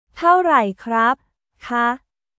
タオライ クラップ／カー